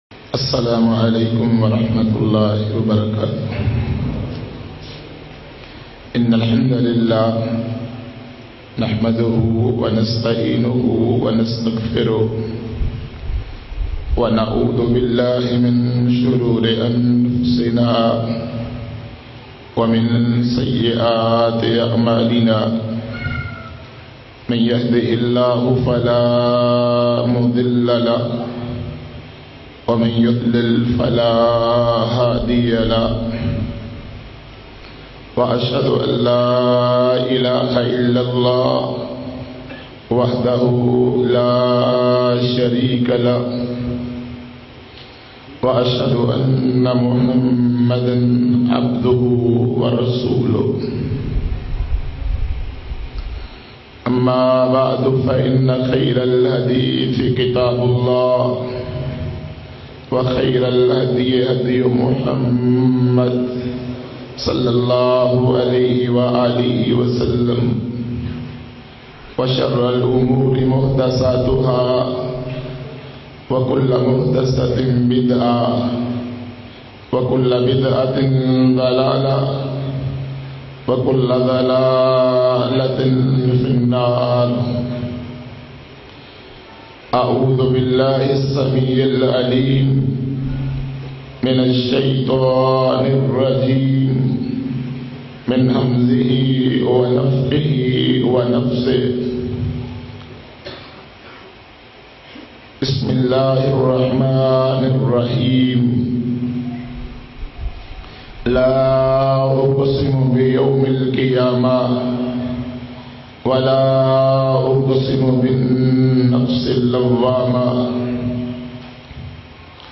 Dilon Ki Islah Kese Mumkin Ke bayan mp3